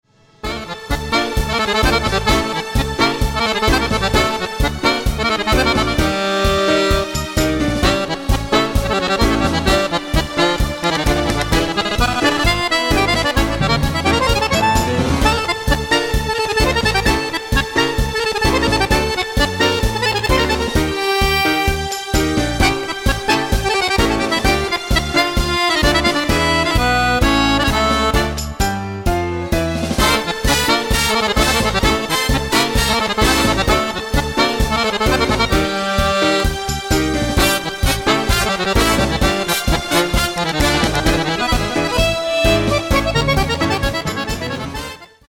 TANGO  (3.41)